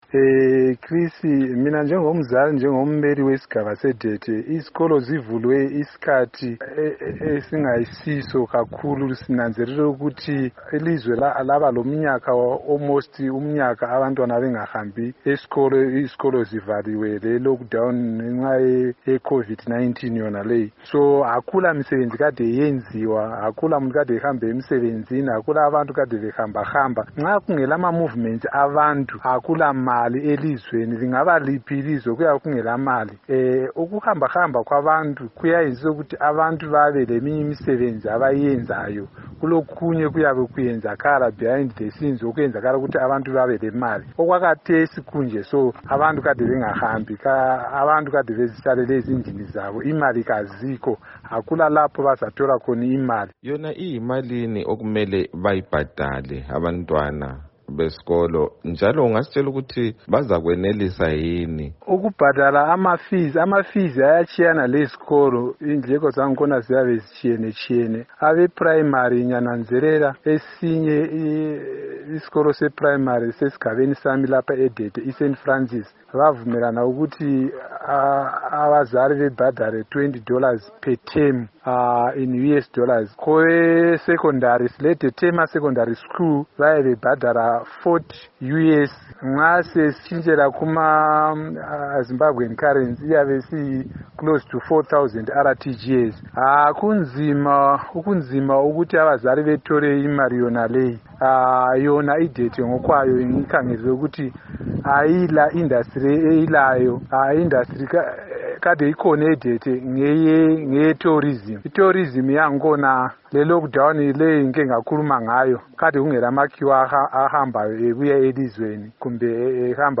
Sixoxe lomzali, uMnu. Stanely Torima ongukhansila njalo weDete esigabeni seMatabeleland North.
Ingxoxo loMnu. Stan Torima